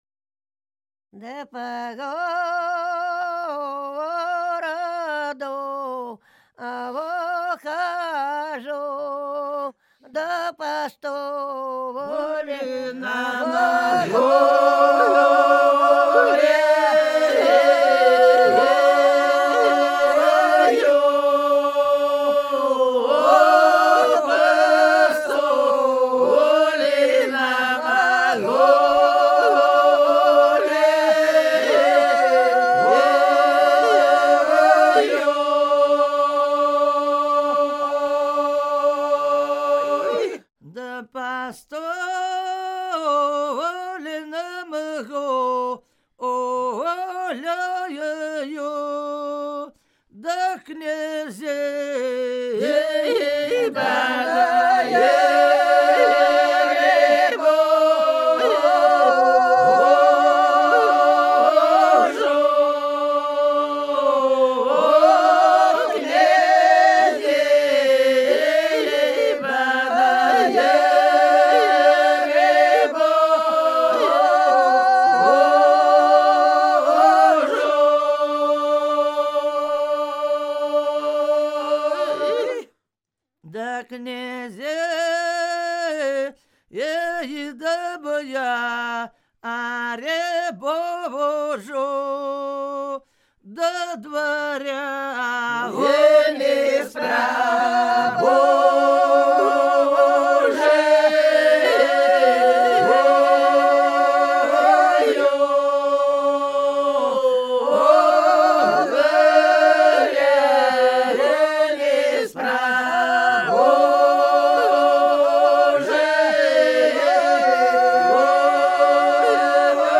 Ансамбль села Хмелевого Белгородской области Да по городу хожу (таночная стяжная, поется под медленный шаг; с Вознесения до Троицы)